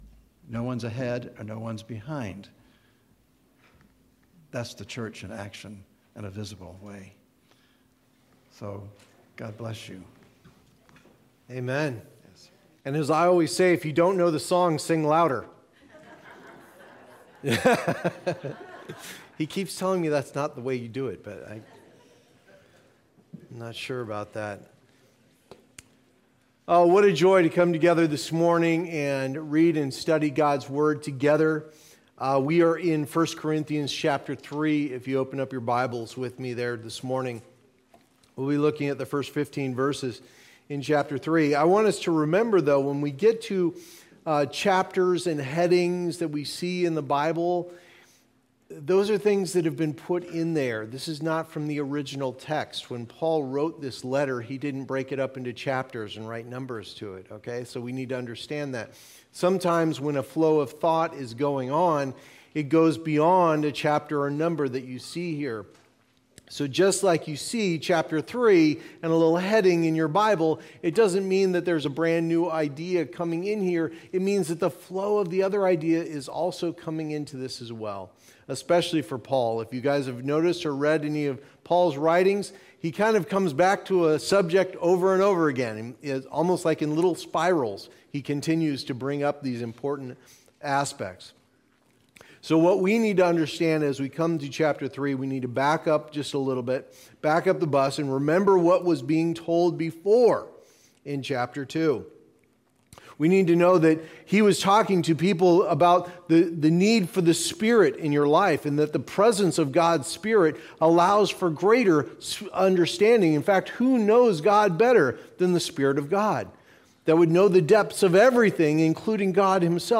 Sunday Morning Service Download Files Notes Previous Next